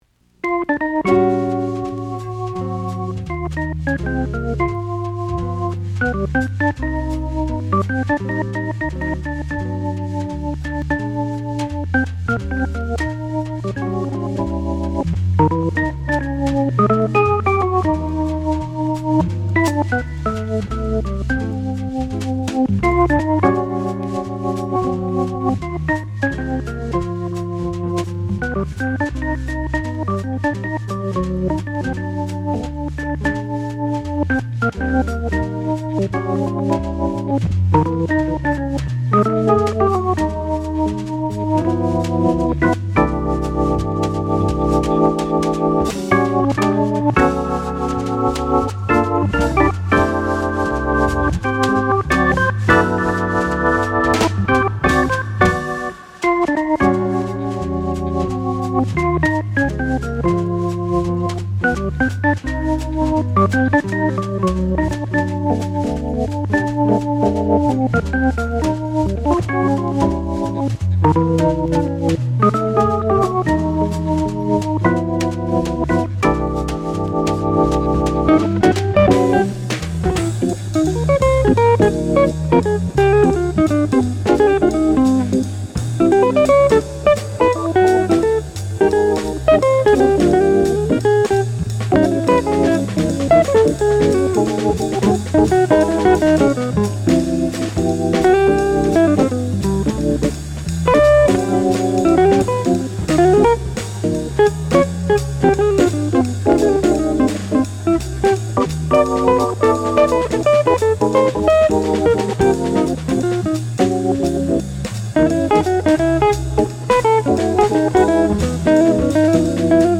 mono pressing